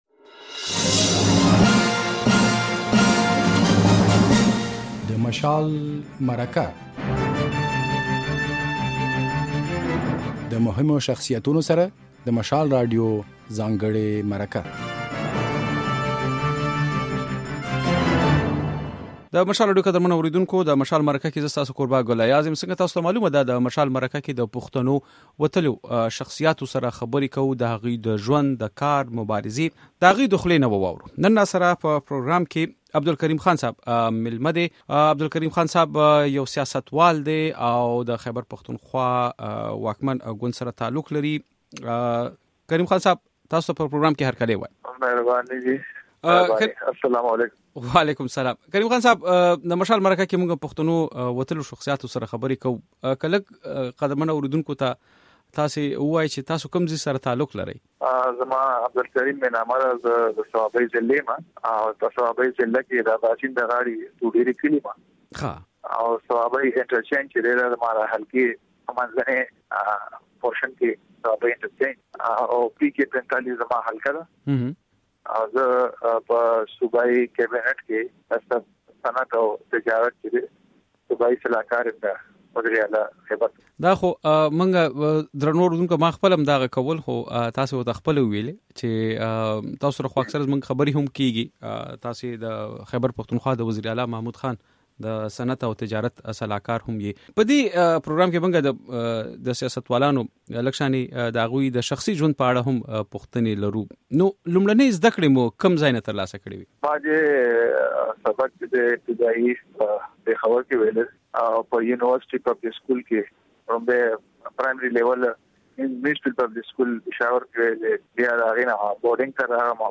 د مشال مرکه خپرونه کې مو د خيبر پښتونخوا د اعلا وزير له سلاکار عبدالکريم خان سره خبرې کړې دي.